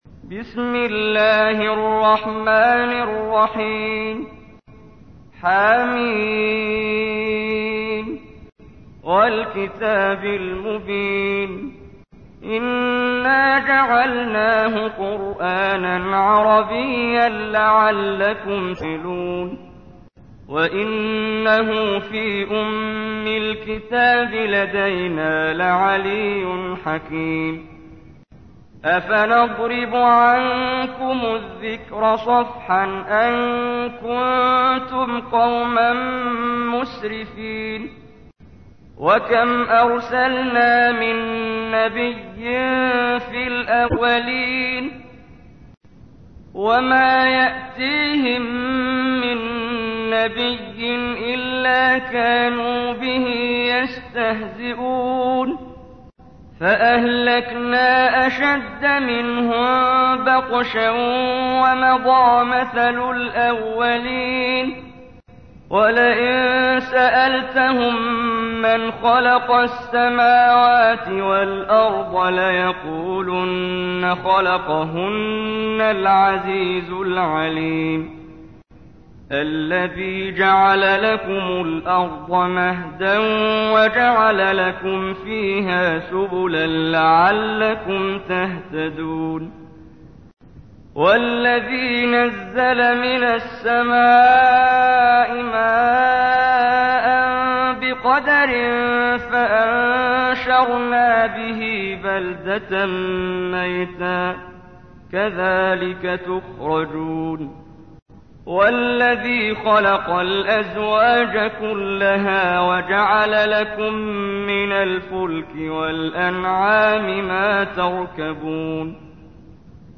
تحميل : 43. سورة الزخرف / القارئ محمد جبريل / القرآن الكريم / موقع يا حسين